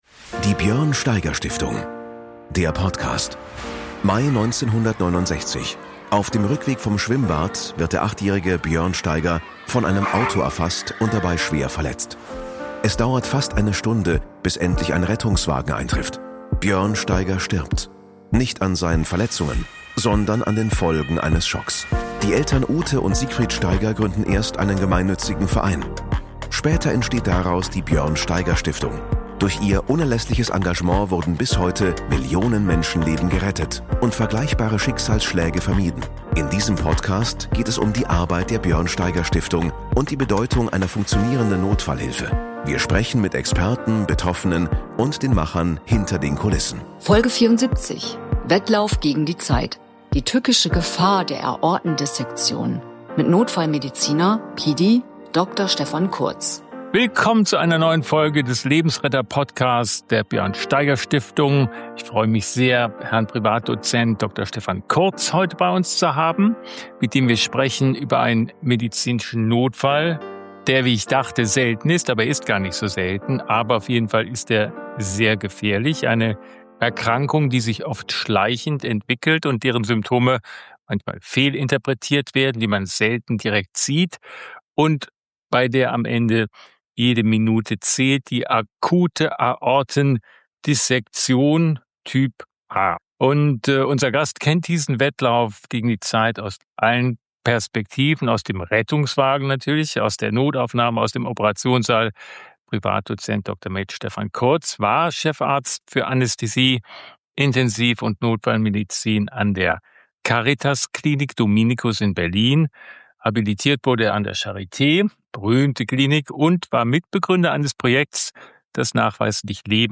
Ein Gespräch über Vernichtungsschmerz, Minuten, die Leben entscheiden – und darüber, wie Prozesse und Strukturen im Rettungswesen Patientinnen und Patienten retten können. Die akute Aortendissektion Typ A ist ein hochgefährlicher Notfall – oft schwer von einem Herzinfarkt zu unterscheiden und unbehandelt nahezu immer tödlich.